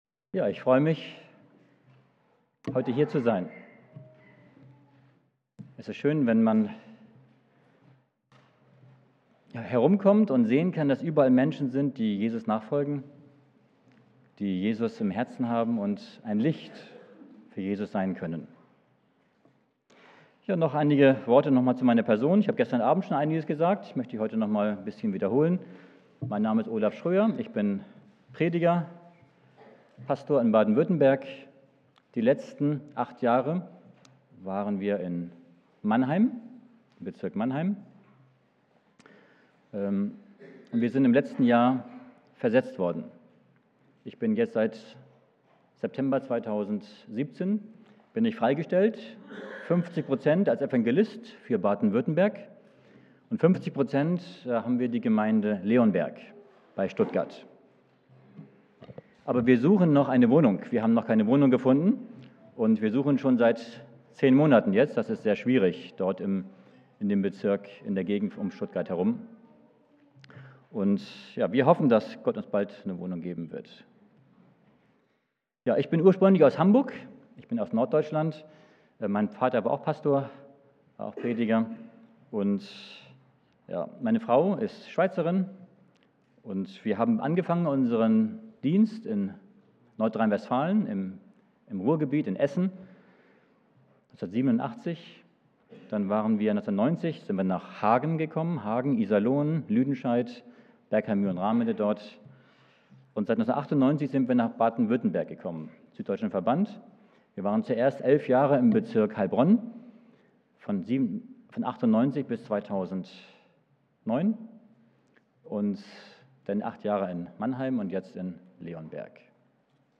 Vorträge